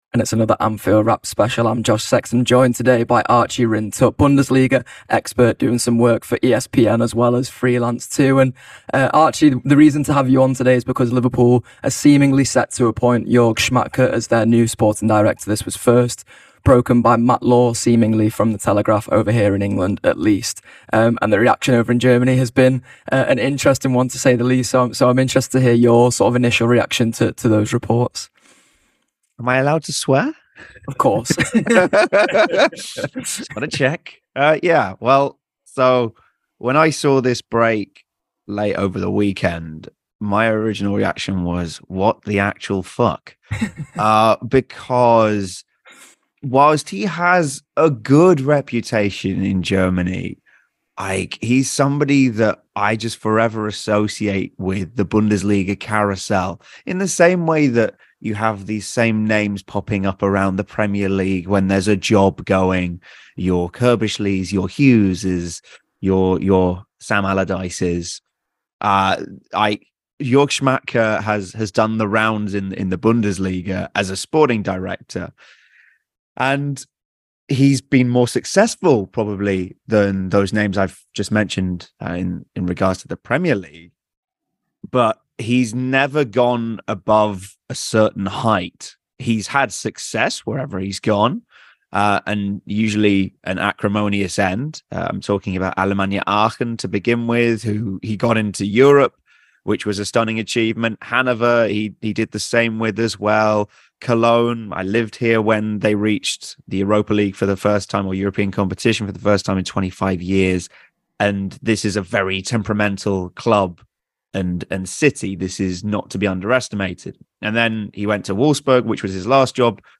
Below is a clip from the show – subscribe for more on Jörg Schmadtke…